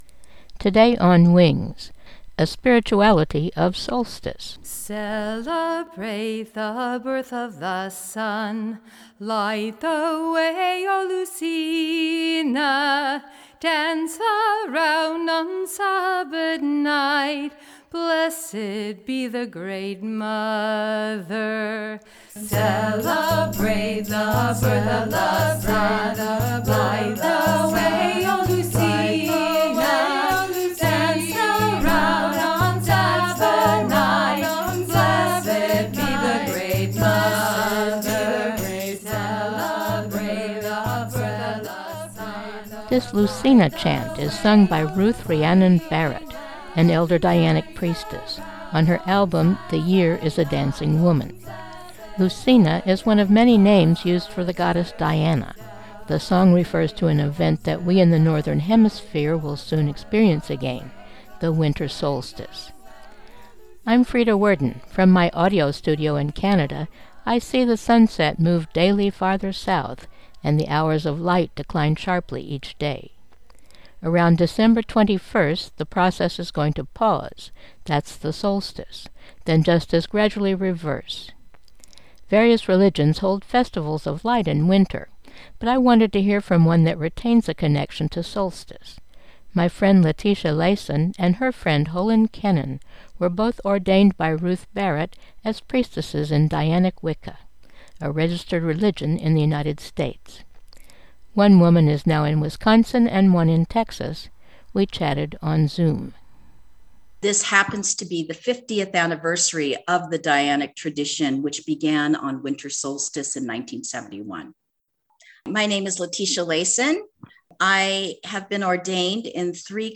They also make suggestions for privately engaging with the darkest night. Lovely music is included.